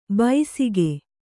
♪ baisige